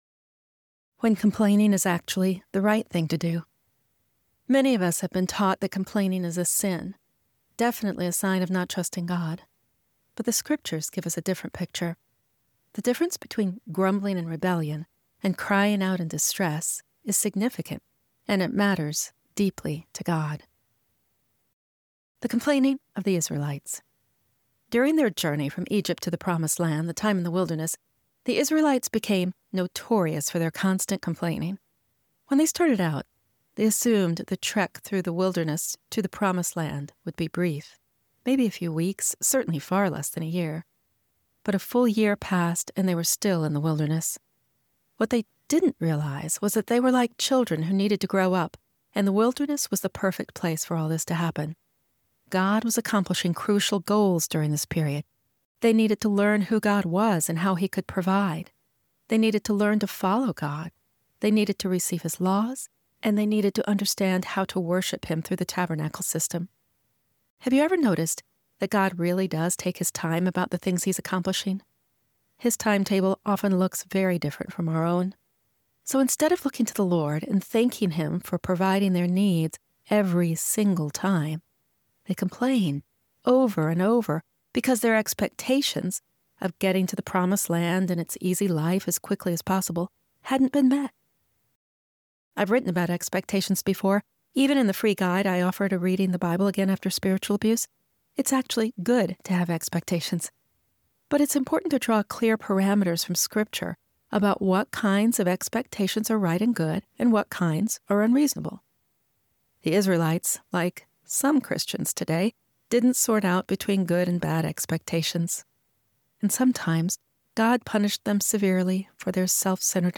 You can listen to me reading this article here: